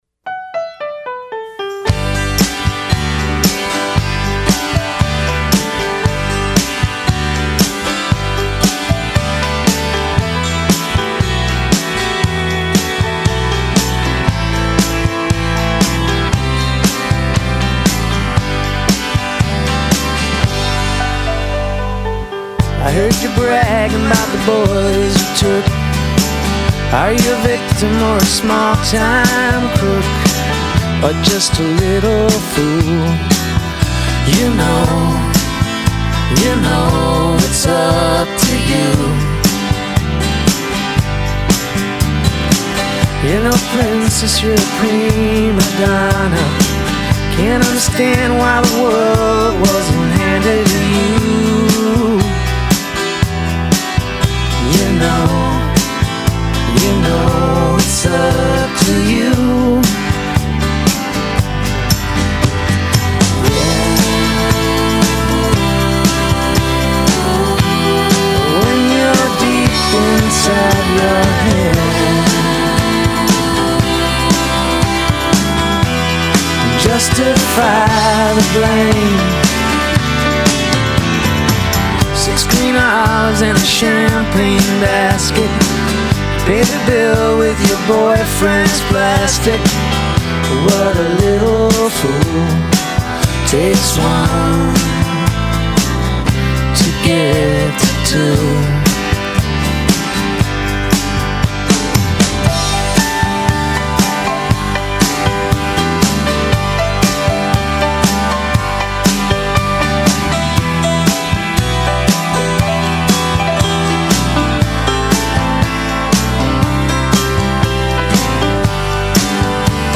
alt country